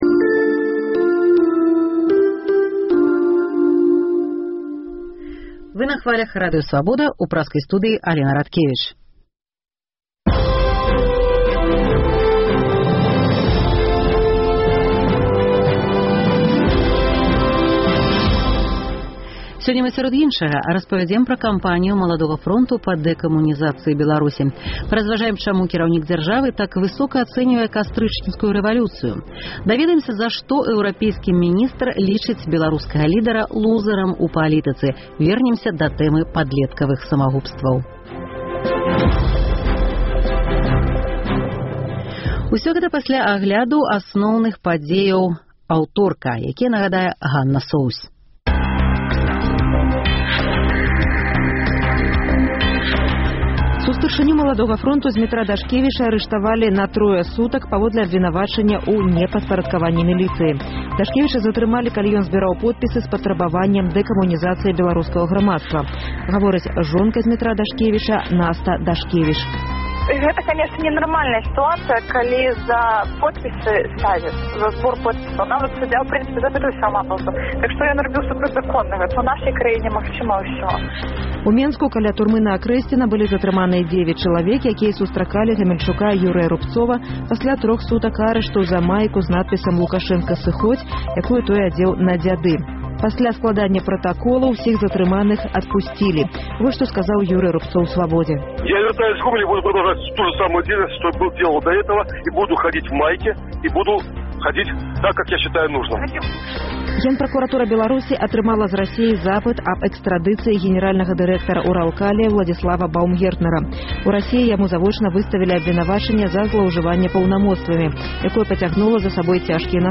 Тэлефануйце ў жывы эфір.